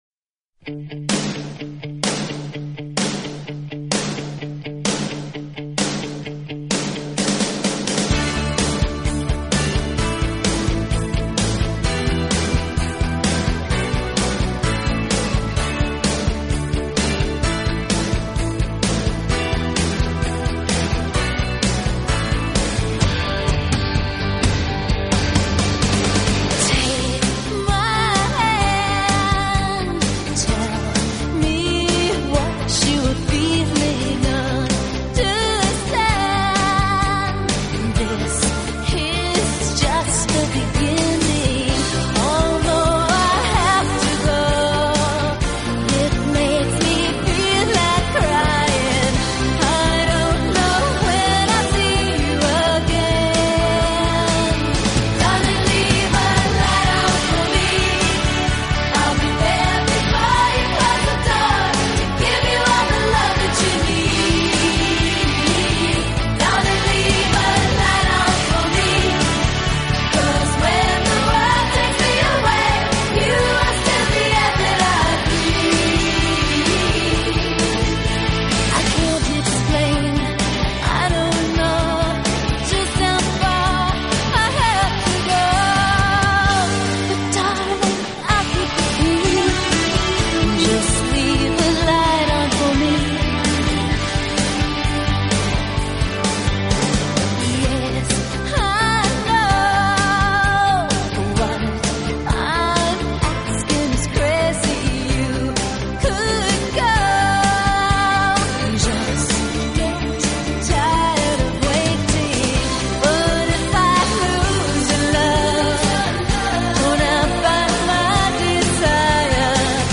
欧美金曲